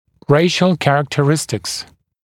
[‘reɪʃl ˌkærəktə’rɪstɪks][‘рэйшл ˌкэрэктэ’ристикс]расовые характеристики